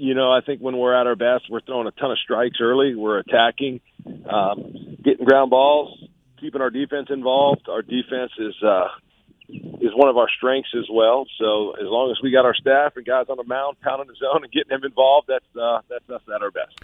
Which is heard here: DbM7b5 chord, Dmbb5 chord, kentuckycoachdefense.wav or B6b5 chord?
kentuckycoachdefense.wav